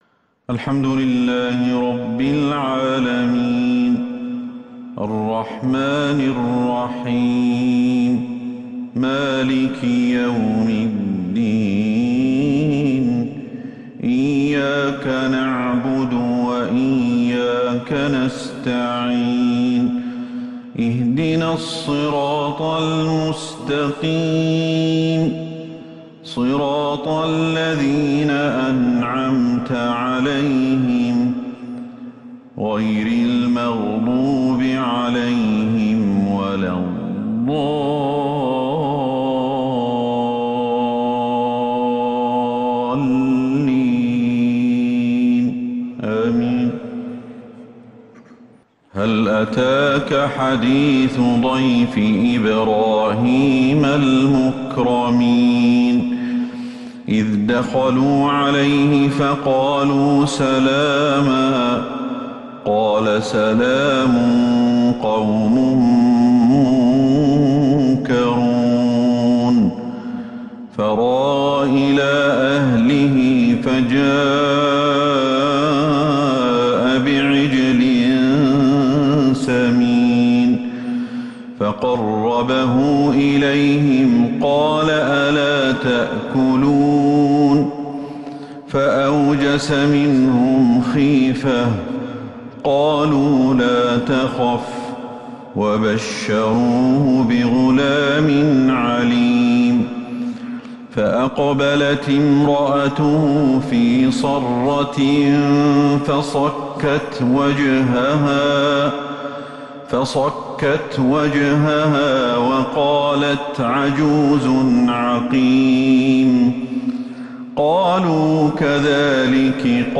عشاء الأحد 4-3-1443هـ من سورة الذاريات | Isha prayer From Surah Adhdhariyat 10/10/2021 > 1443 🕌 > الفروض - تلاوات الحرمين